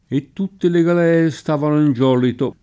galea [ g al $ a ] s. f. («nave»)